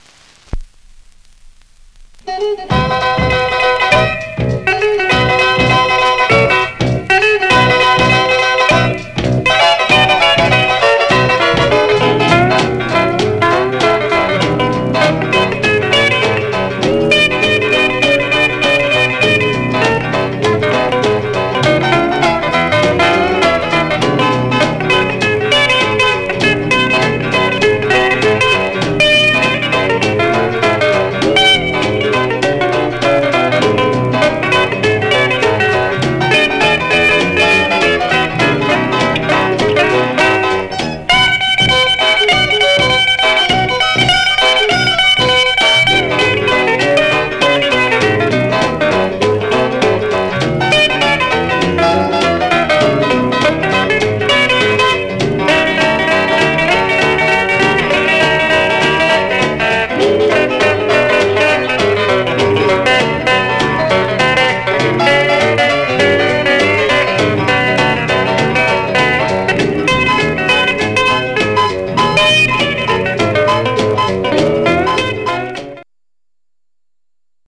Some surface noise/wear
Mono
R&B Instrumental